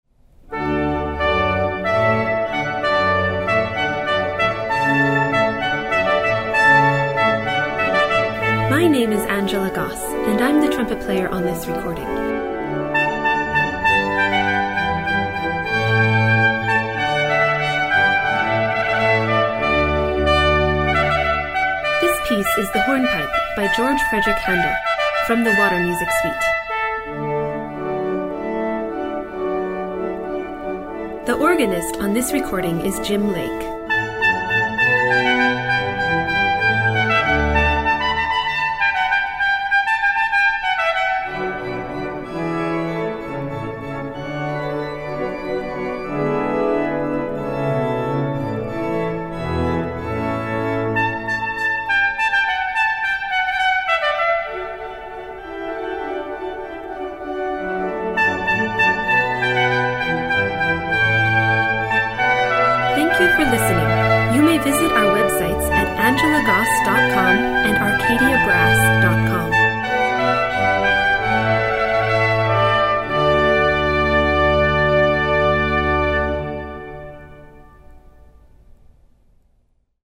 festive music (mp3) for your recessional and receiving line.